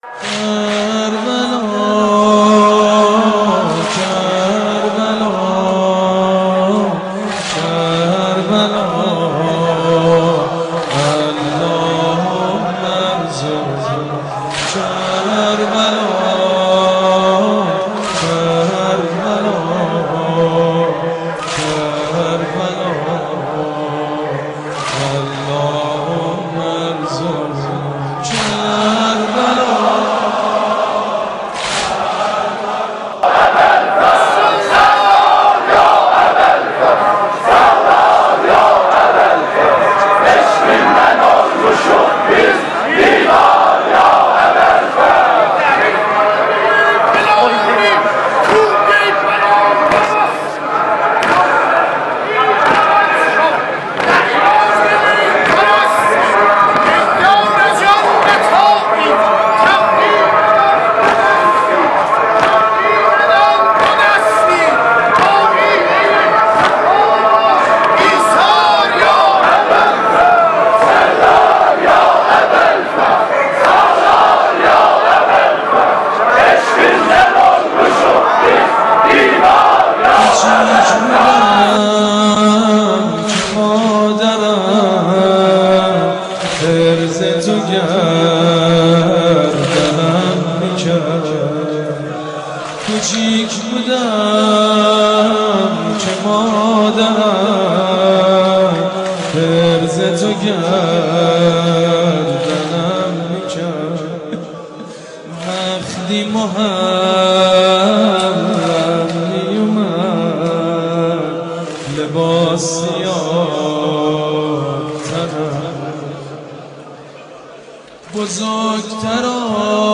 مداحی؛ کربلا کربلا اللهم الرزقنا
گزیده ای از لحظات پایانی عزاداری مردم در حسینیه اعظم زنجان در شب هشتم محرم